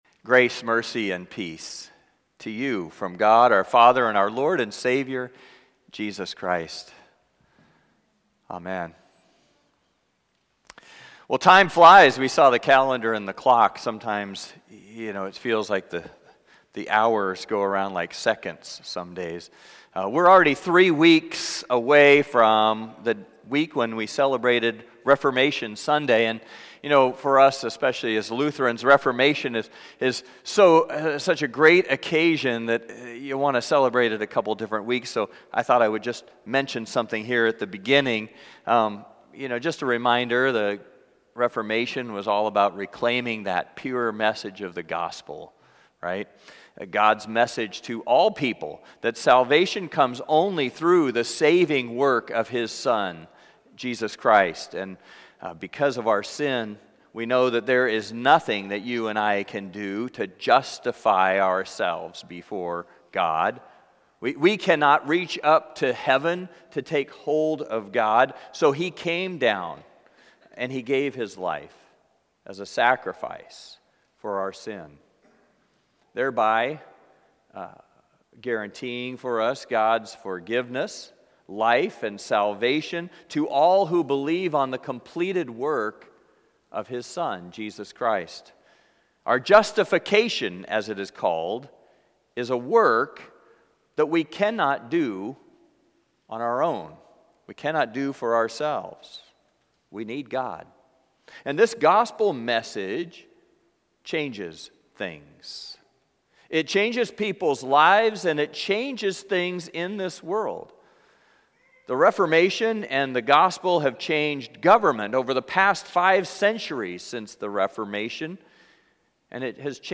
Passage: Luke 21:5-19 Service Type: Traditional and Blended